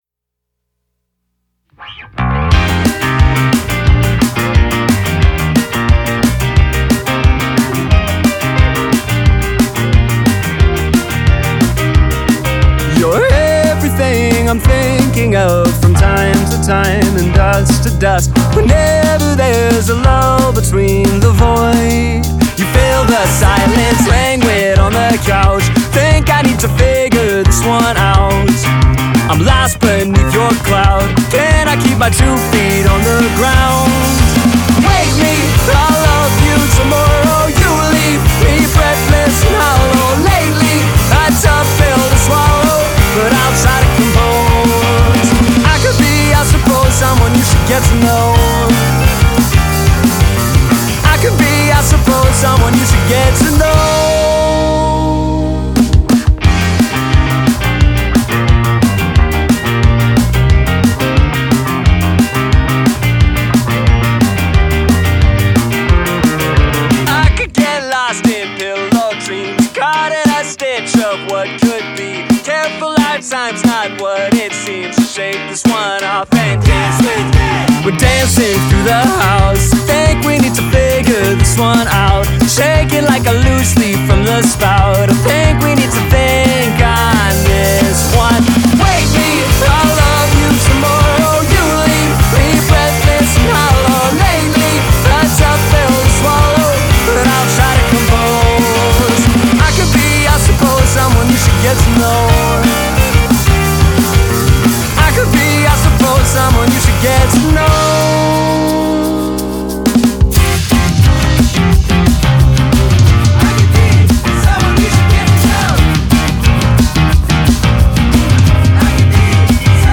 BPM178-178
Audio QualityPerfect (High Quality)
Indie song for StepMania, ITGmania, Project Outfox
Full Length Song (not arcade length cut)